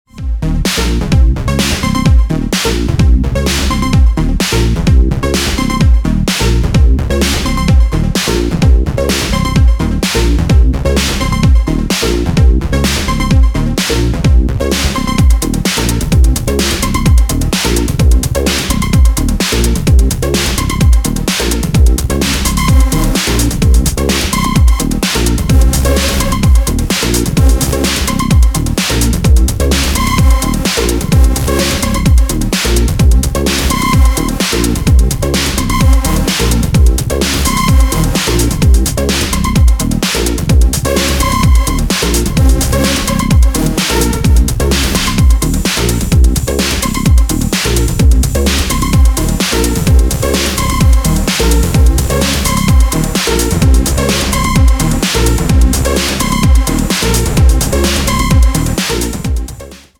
デトロイト・エレクトロ的近未来感を醸すリフがカッコいい